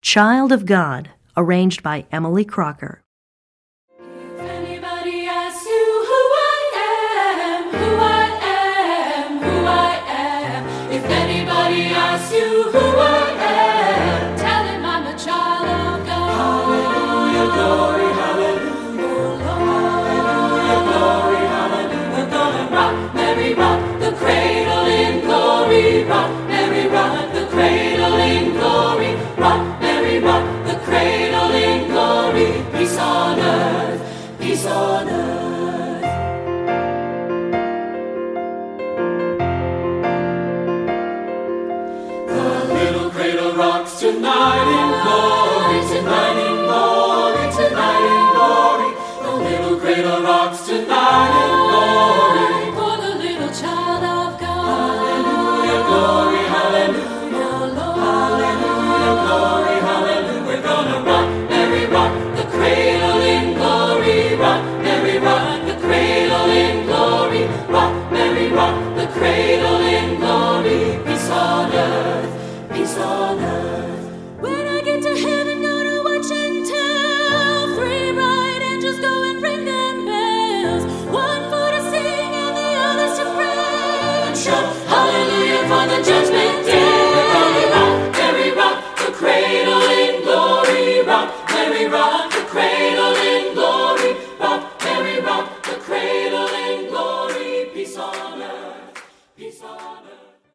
Composer: Traditional Spiritual
Voicing: SAT